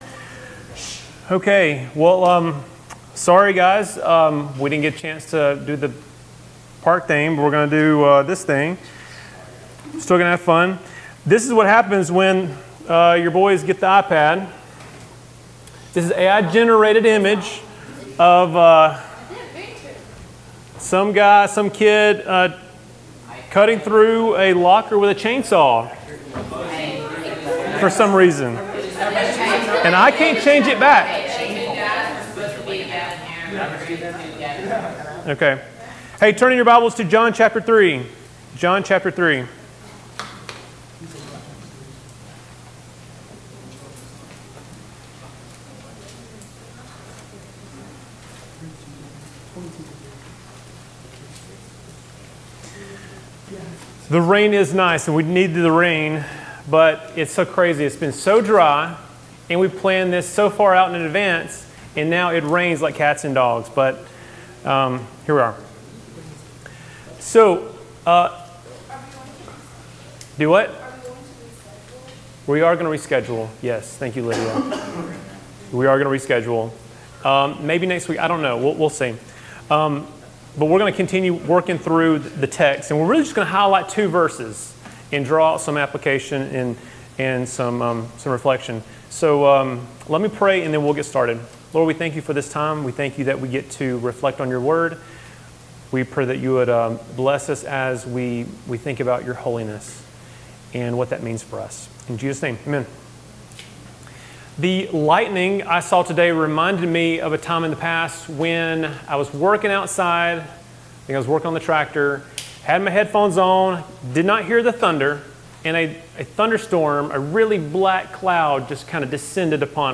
Messages from our Wednesday Night Student Services.